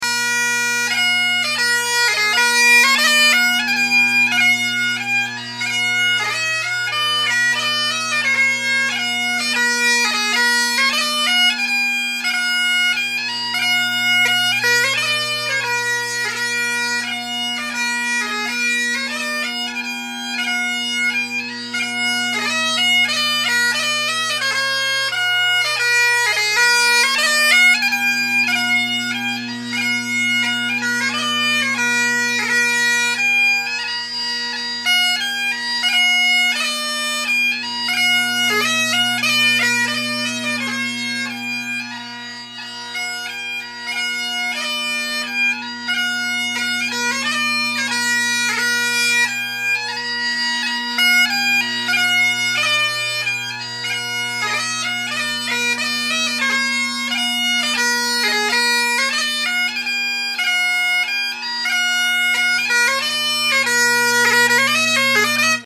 The top hand F, high G, and high A all sound very good. D is spot on, as is B. The other notes are a given.